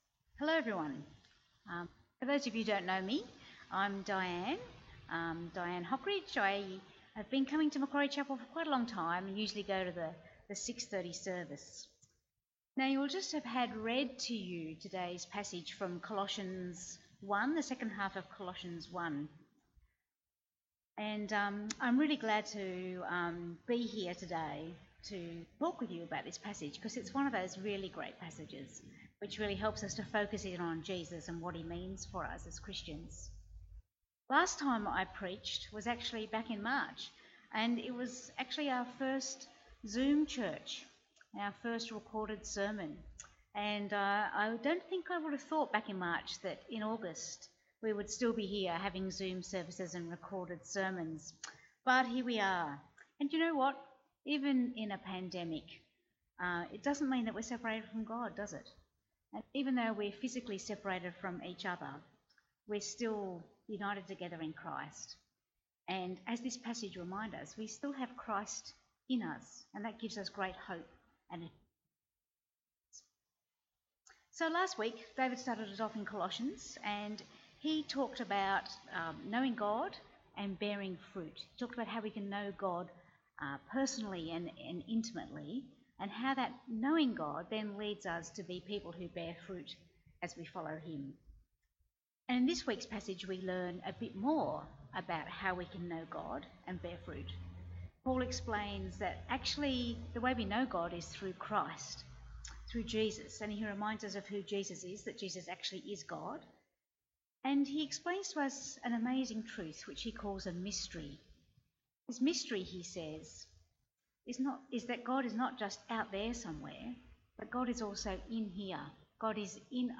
Bible Text: Colossians 1:15-29 | Preacher